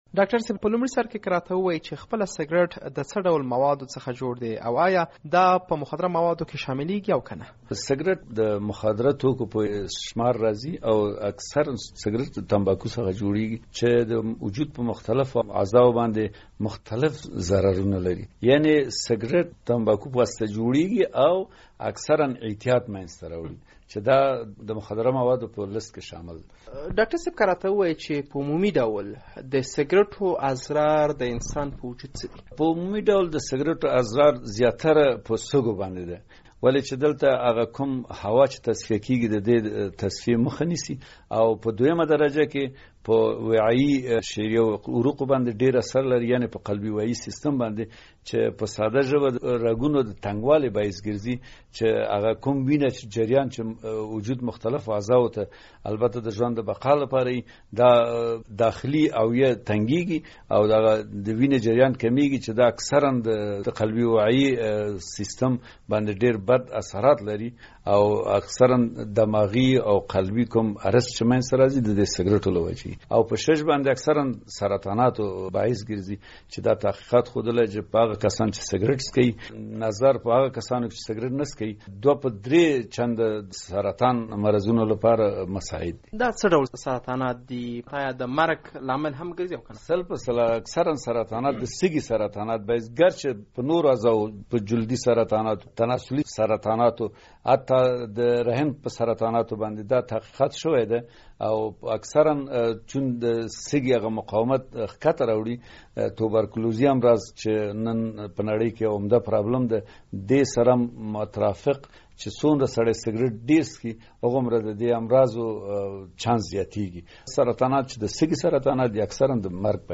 د زهرو کاروان پروګرام مرکه